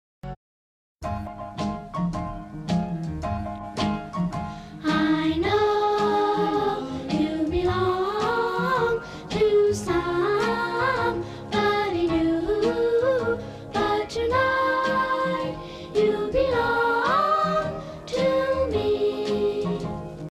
aterrizaje de emergencia sale mal sound effects free download